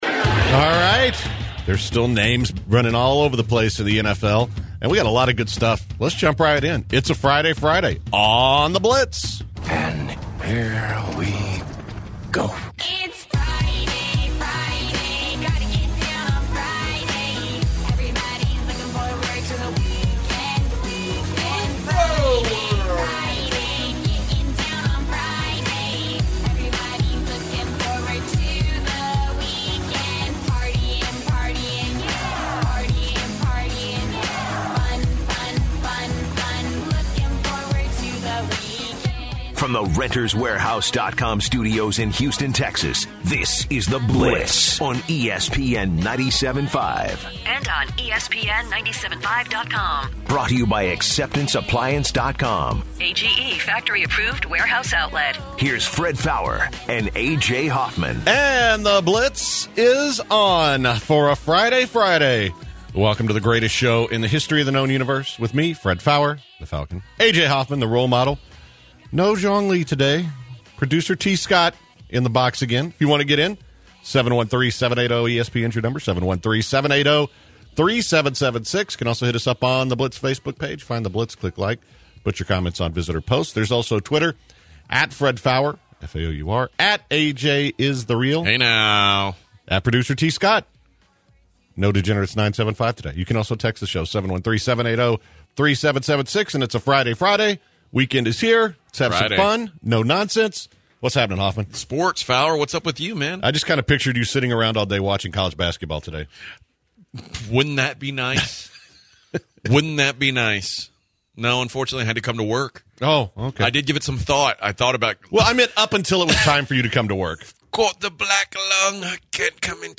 QB's were heavy in The Blitz once again. The guys mainly talked and took calls on QB's specifically Tony Romo.